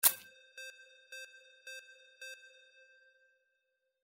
Stereo sound effect - Wav.16 bit/44.1 KHz and Mp3 128 Kbps